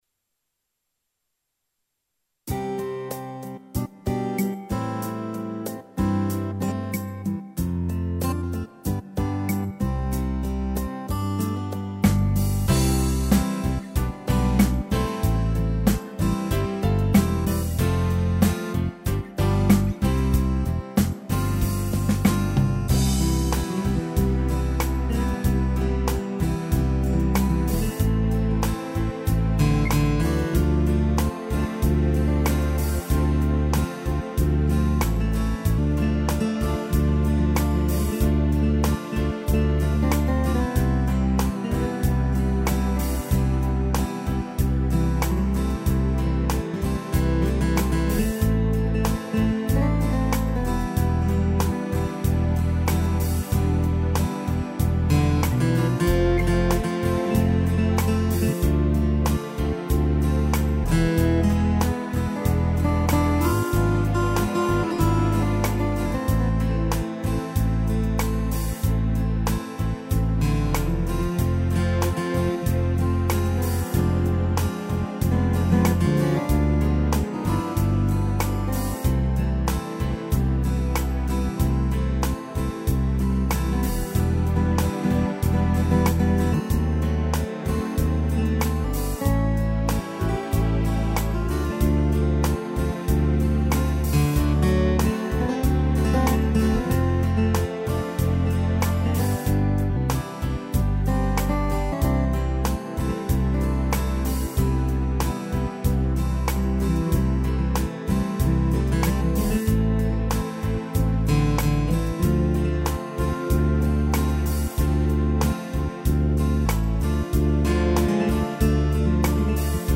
instrumental
arranjo e interpretação teclado